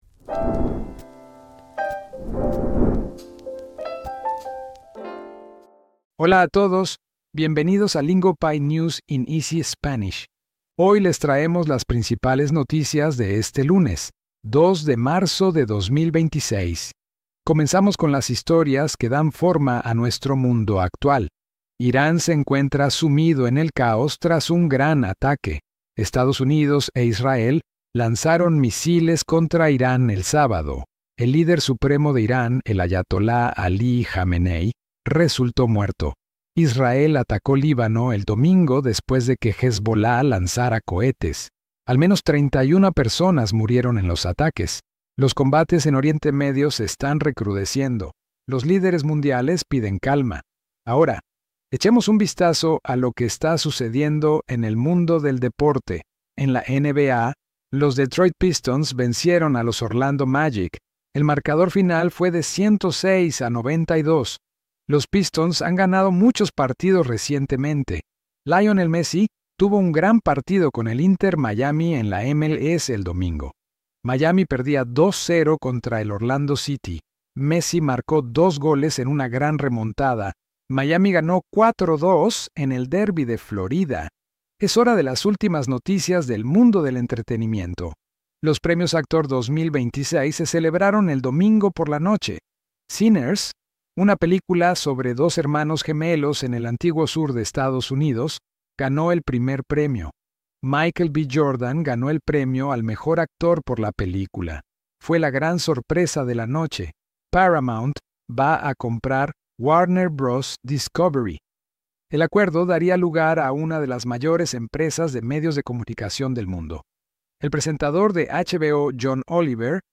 We deliver each story in clear, beginner-friendly Spanish, so you can follow the meaning without getting stuck replaying every line.